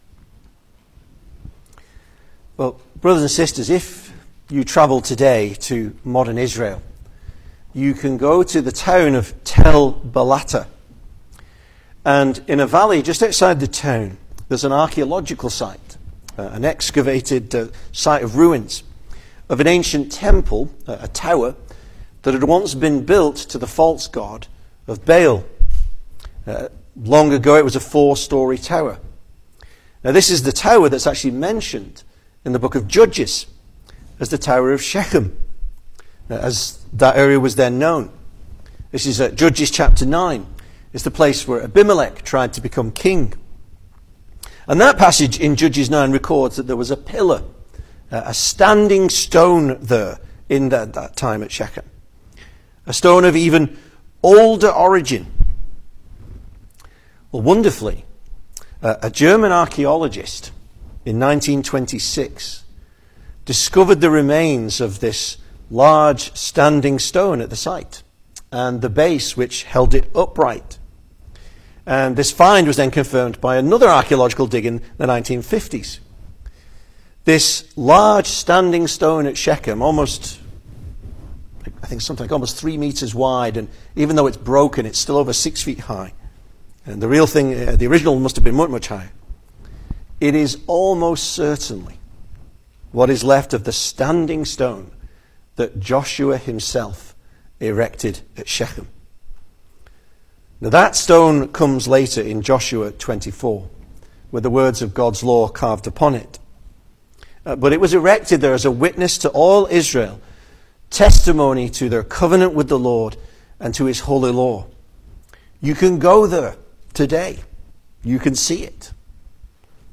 2026 Service Type: Sunday Evening Speaker